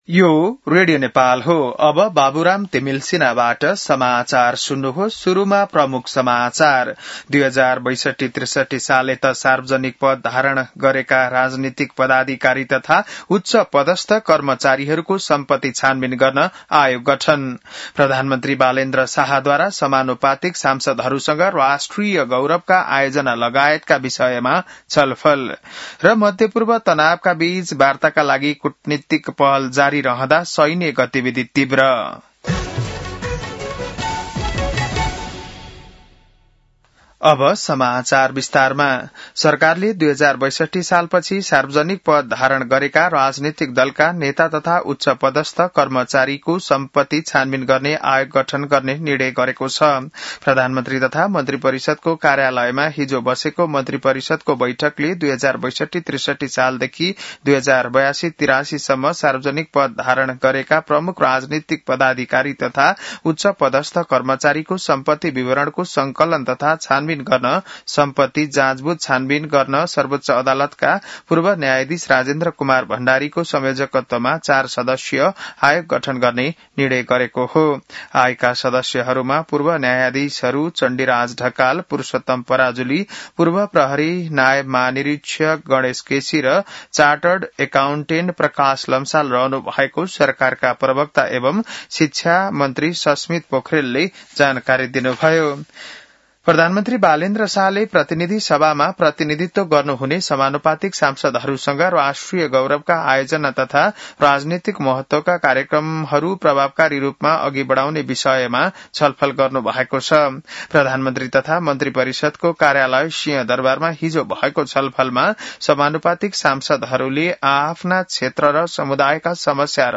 बिहान ९ बजेको नेपाली समाचार : ३ वैशाख , २०८३